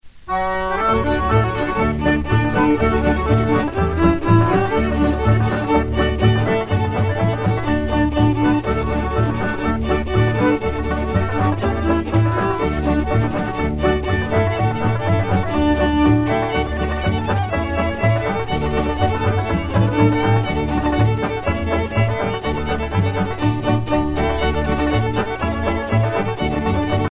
danse : square dance